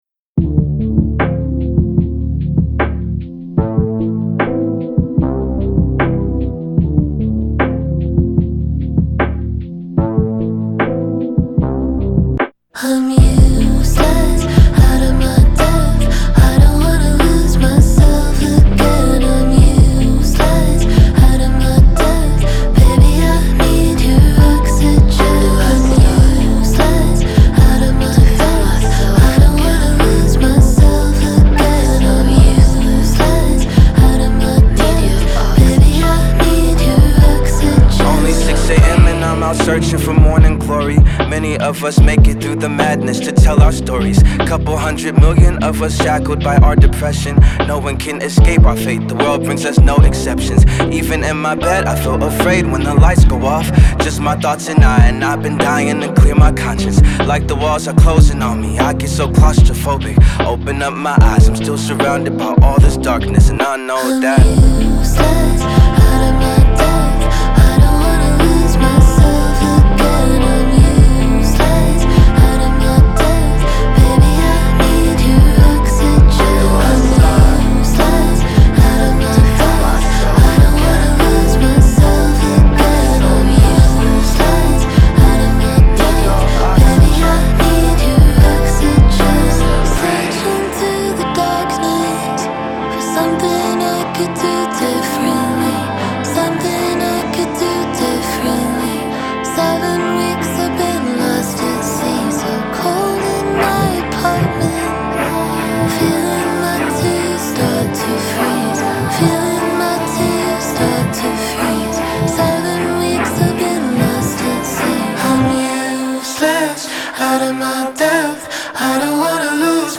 • Жанр: Soul, R&B